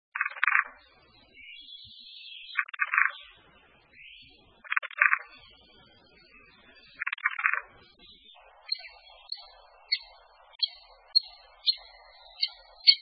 En cliquant ici vous entendrez le chant du Pic épeiche.
Le Pic épeiche